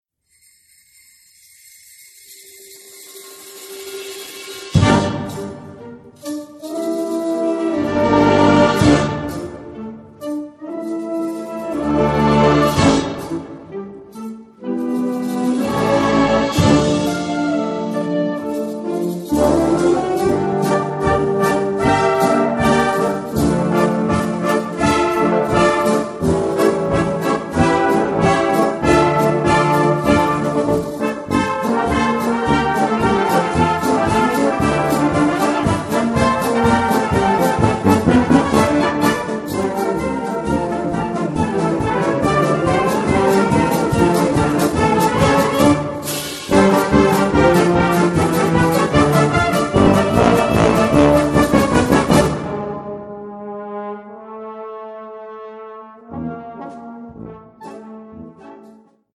• Besetzung: Blasorchester